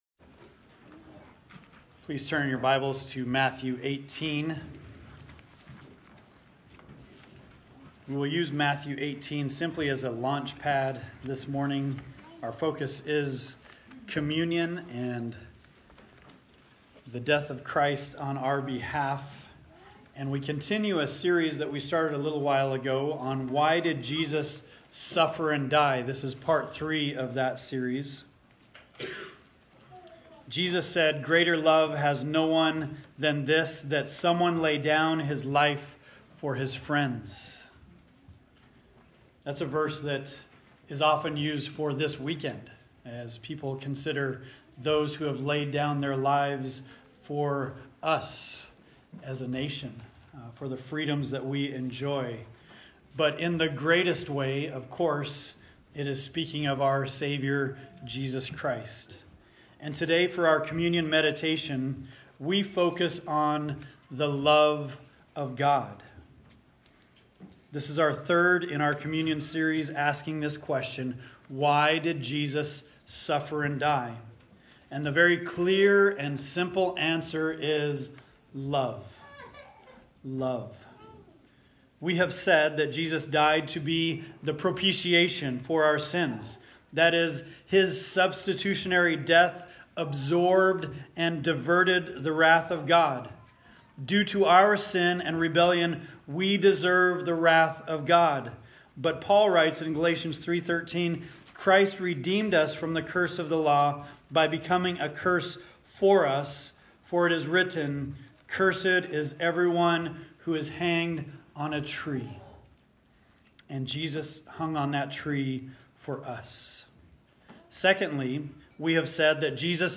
Communion Meditation Passage: Matthew 18:12-14 Topics